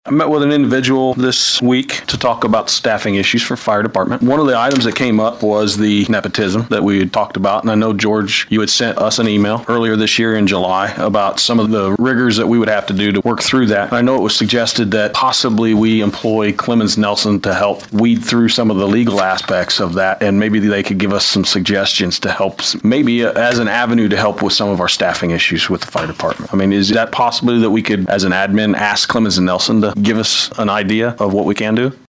Celina City Council meeting for December 16th
At Last Night’s Meeting, The Celina City Council Heard From Councilman Matt Gray Who Discussed A Recent Meeting He Had Concerning Staffing At The Celina Fire Department.